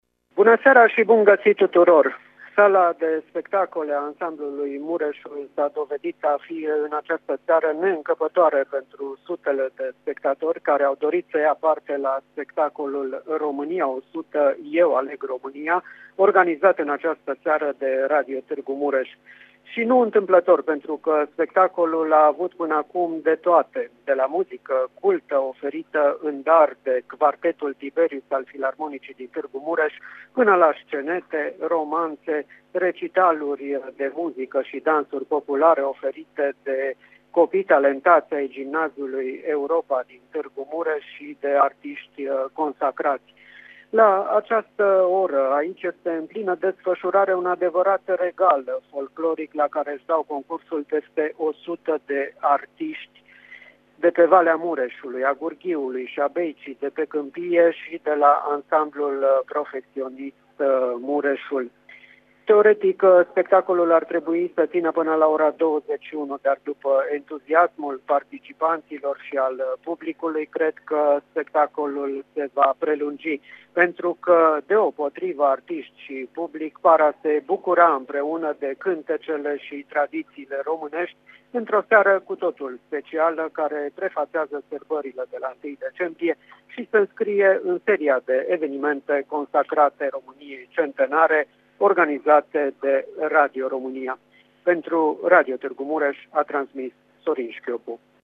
Radio Tg.Mureș, alături de ascultătorii săi, dar și de mari artiști din tot județul sărbătoresc în avans acest mare eveniment la sala Ansamblului Mureșul din Tg. Mureș.
relatare.mp3